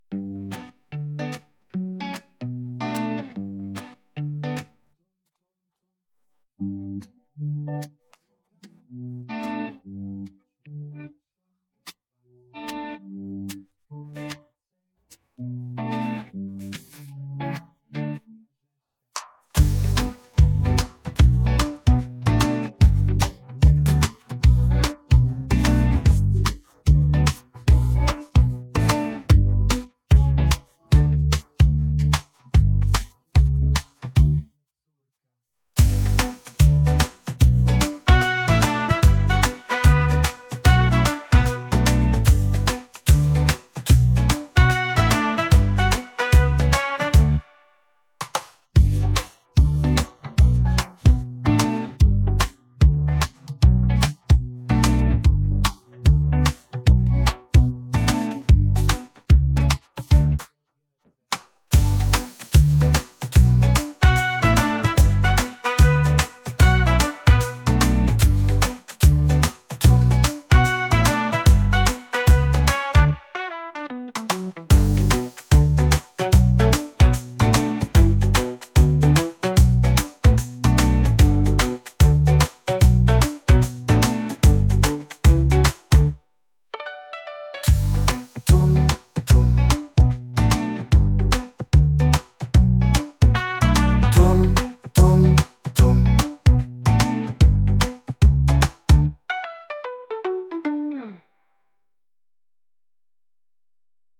Podkład muzyczny tytuł: Na drogã , autor: inteligencja Sztuczna Odsłuchań/Pobrań 2
Nagranie wykonania utworu